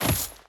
Footsteps
Dirt Chain Land.wav